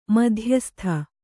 ♪ madhyastha